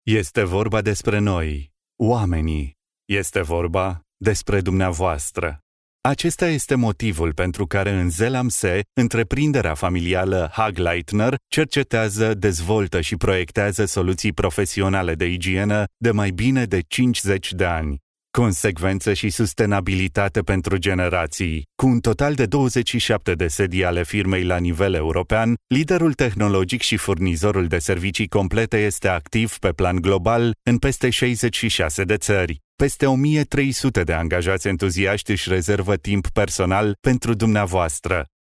Experienced Romanian male, native, voice talent
Romanian male - corporate sample